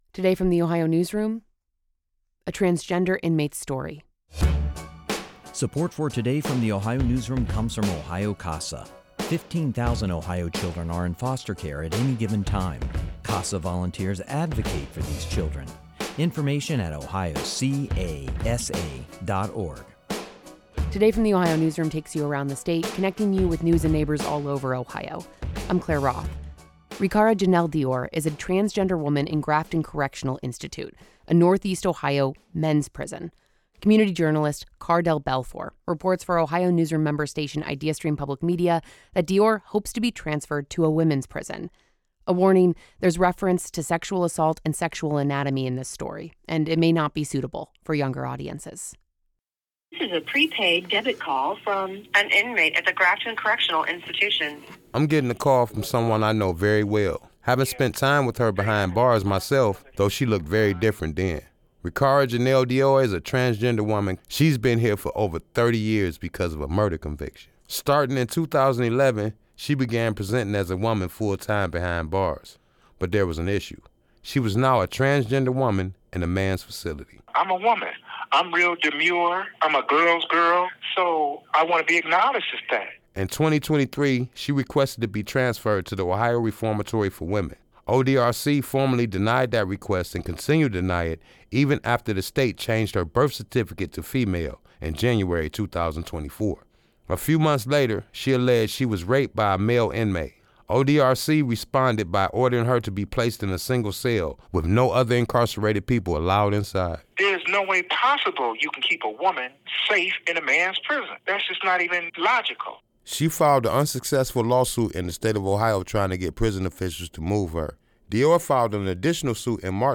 The Ohio Newsroom talked with her over the phone because the Ohio Department of Rehabilitation and Corrections denied a request to speak in person, citing safety concerns.